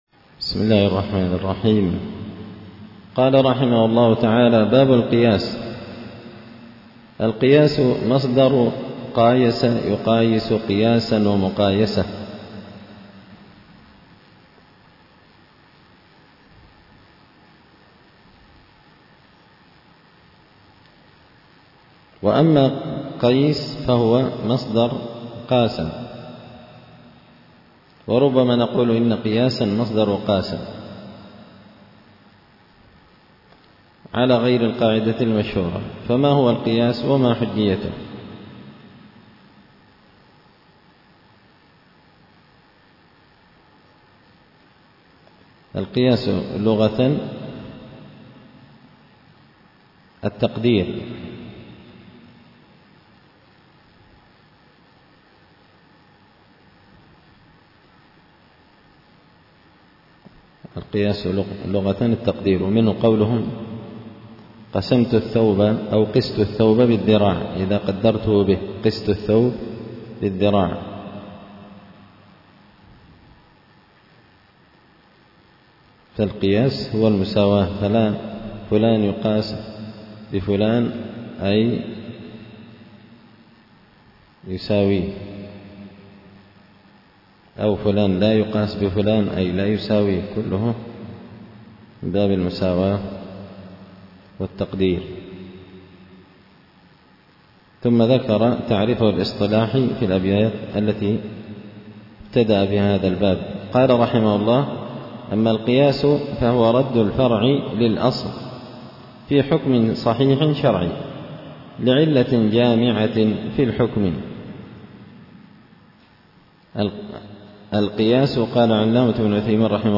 التعليقات على نظم الورقات ـ الدرس 50
دار الحديث بمسجد الفرقان ـ قشن ـ المهرة ـ اليمن